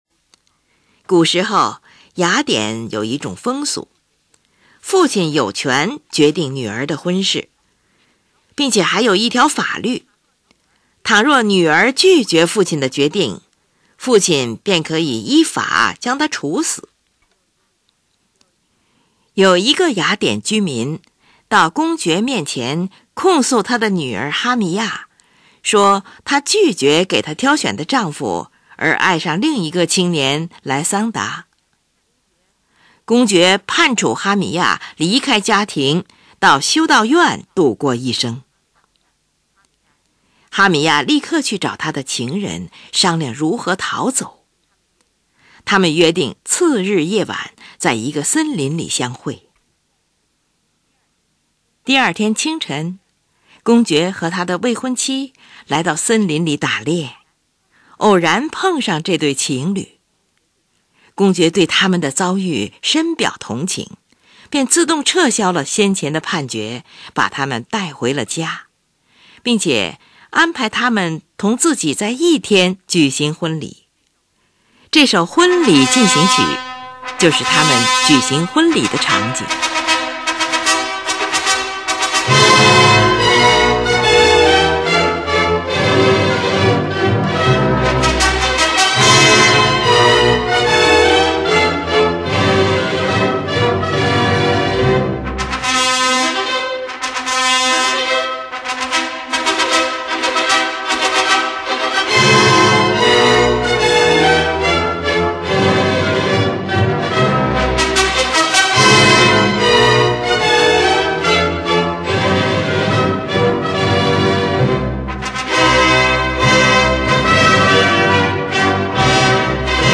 用于行军或游行的音乐一定是二拍子（2/4或6/8）或四拍子。